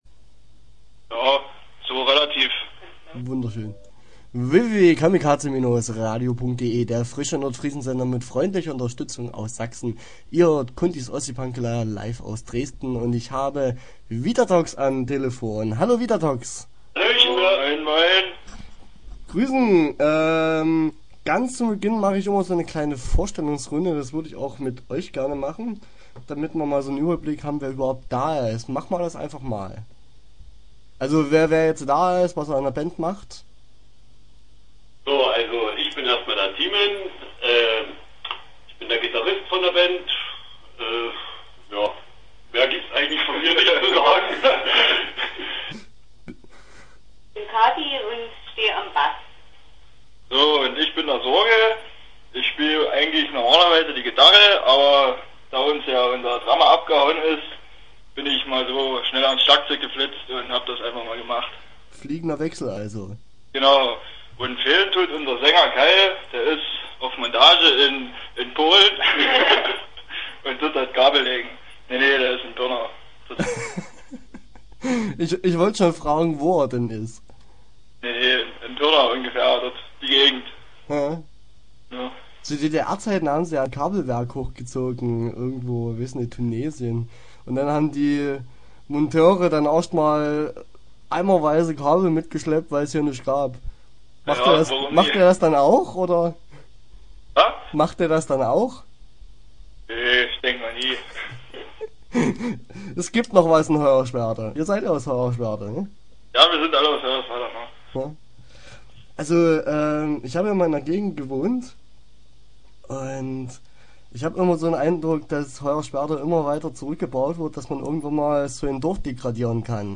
Interview Teil 1 (11:38)